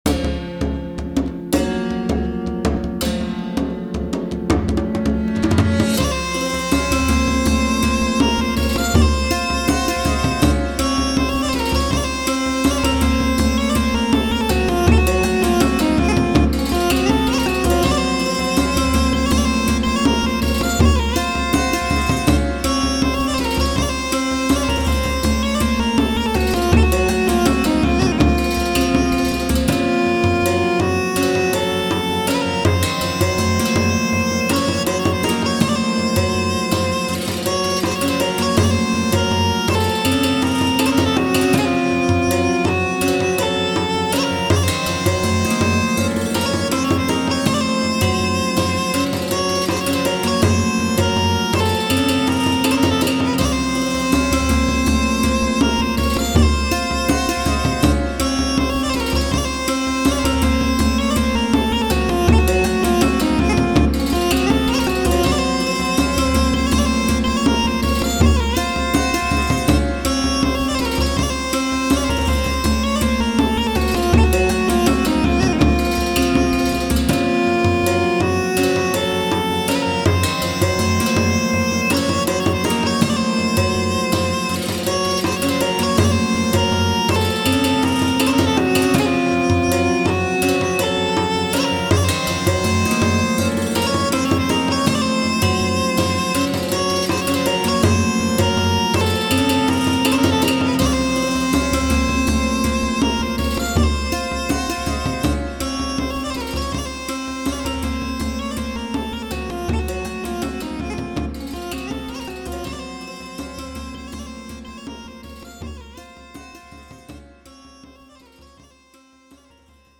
ファンタジー世界っぽい民族音楽。 トルコ、イタリア、インドの民族楽器、民族音楽の要素を組み合わせて作っている。
タグ: ファンタジー フィールド楽曲 冒険 民族音楽 コメント: ファンタジー世界っぽい民族音楽。